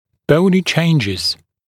[‘bəunɪ ‘ʧeɪnʤɪz][‘боуни ‘чэйнджиз]костные изменения